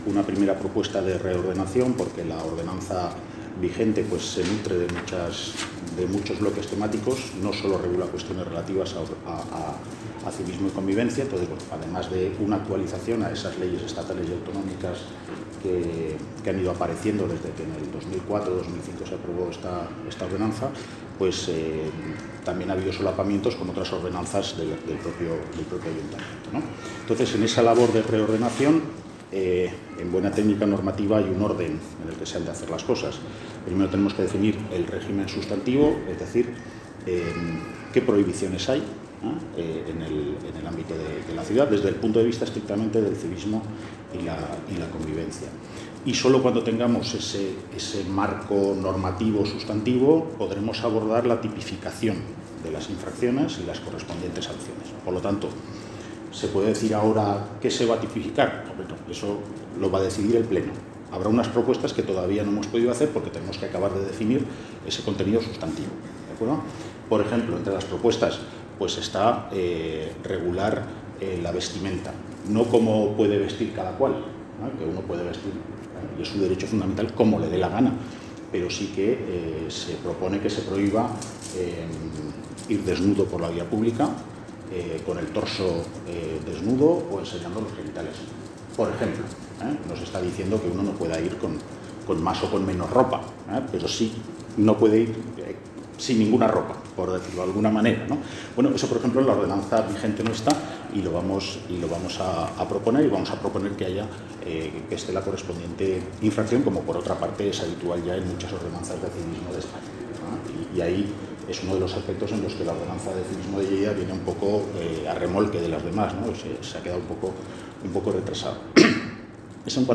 Tall de veu 1 de l'alcaldessa accidental, Cristina Morón, sobre la proposta de modificació i actualització de l’Ordenança Municipal de Civisme i Convivència